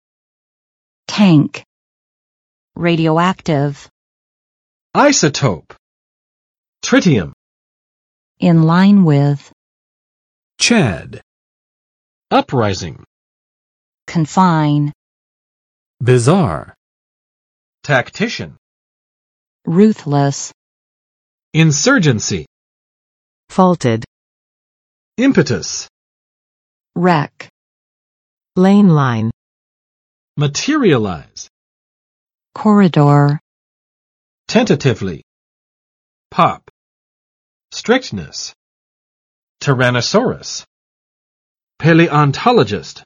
[tæŋk] n. 箱，槽；贮水池